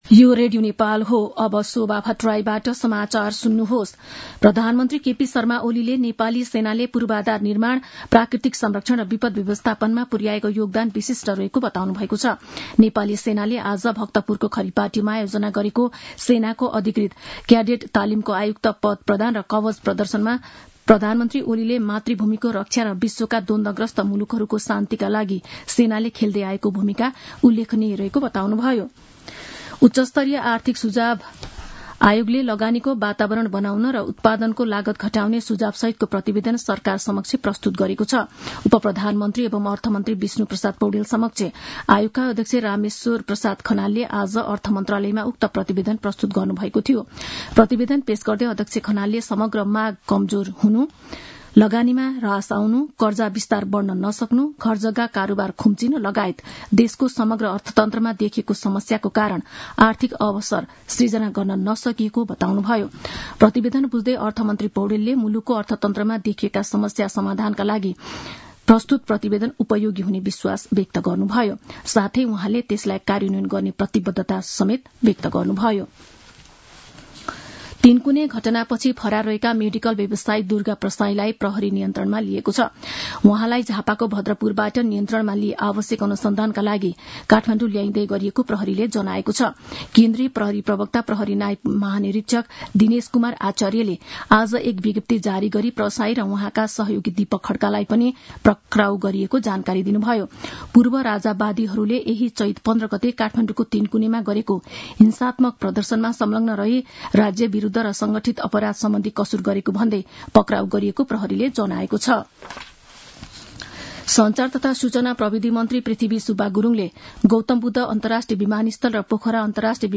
An online outlet of Nepal's national radio broadcaster
मध्यान्ह १२ बजेको नेपाली समाचार : २९ चैत , २०८१